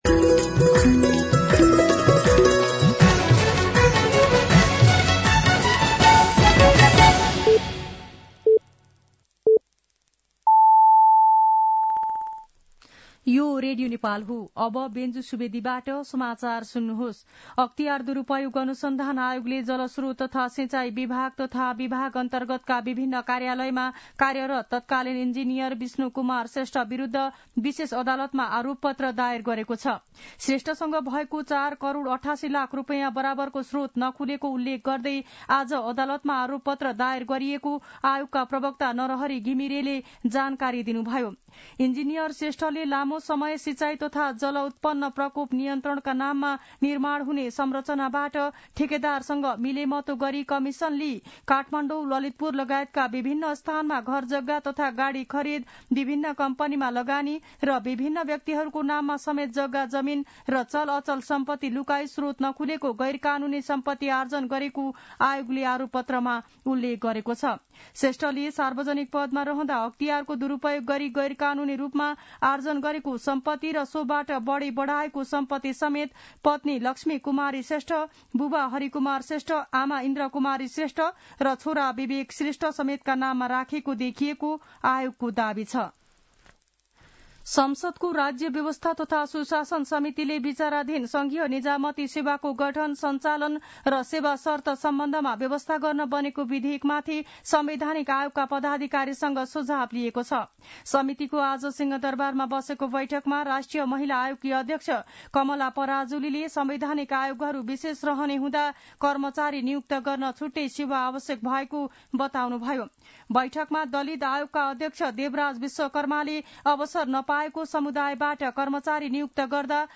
साँझ ५ बजेको नेपाली समाचार : २९ पुष , २०८१
5-PM-Nepali-News-9-28.mp3